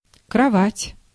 Ääntäminen
IPA: /sɛŋ/